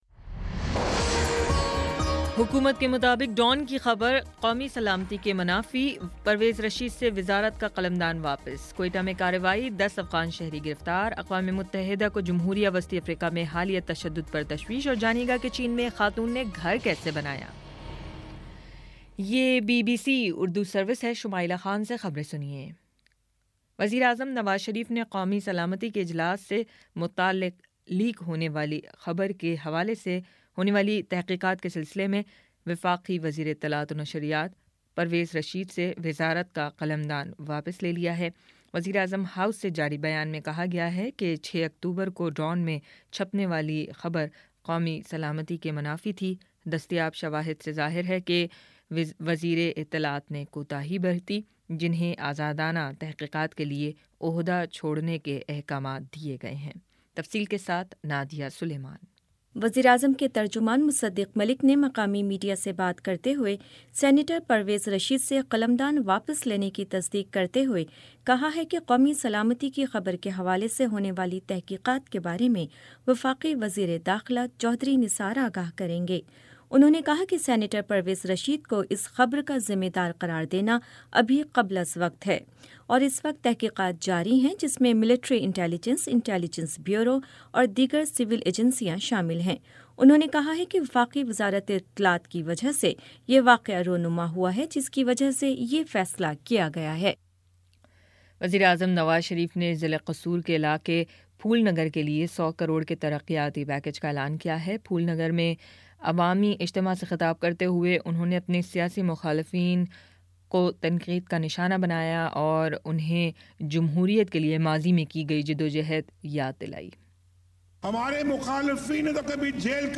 اکتوبر 29 : شام چھ بجے کا نیوز بُلیٹن